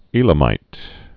(ēlə-mīt)